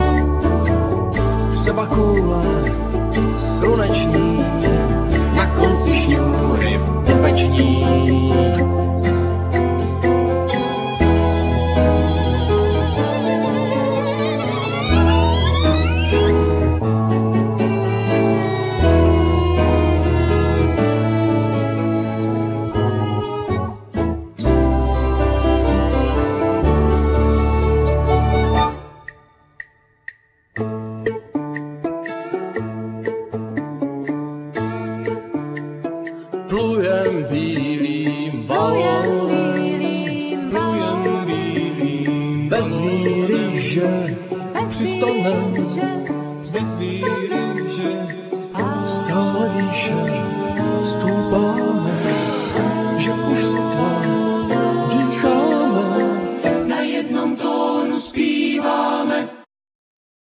Vocal,Piano
Guitar,Vocal,Bass
Violin
Clarinet
Percussions
El.guitar